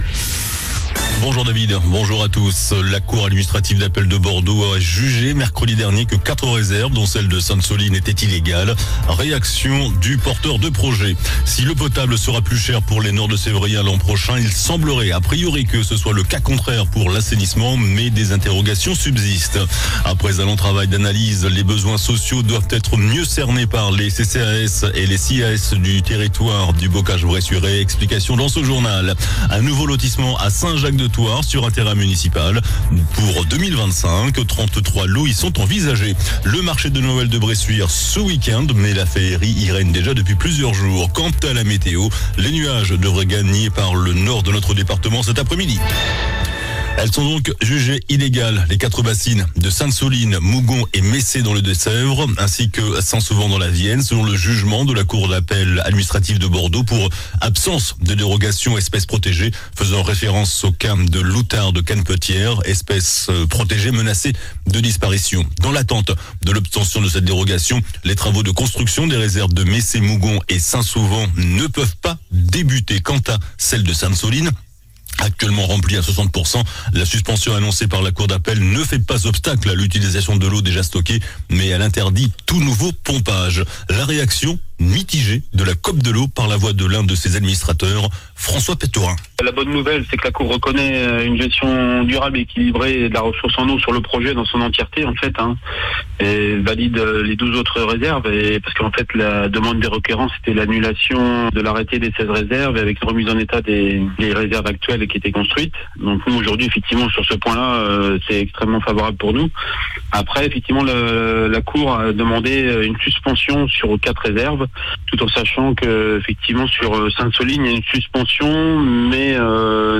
JOURNAL DU VENDREDI 20 DECEMBRE ( MIDI )